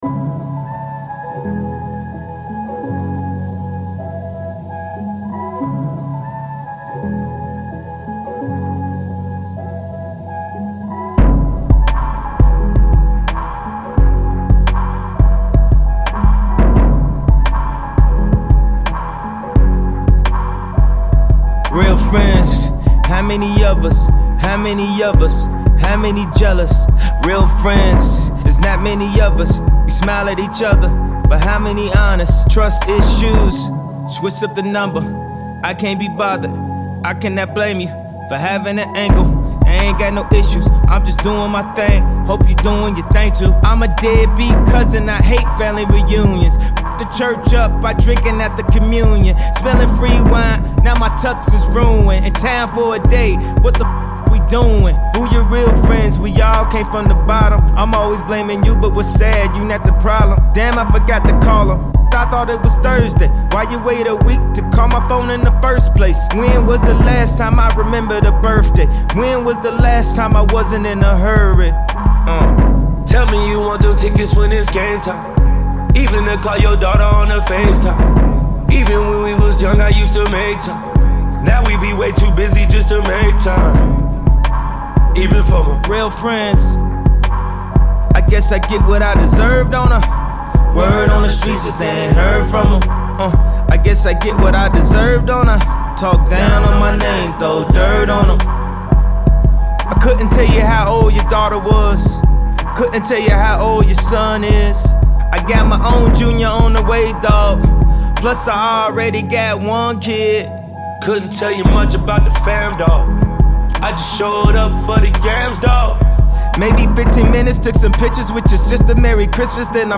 DJ Service Pack with Intro, Clean & Dirty Versions.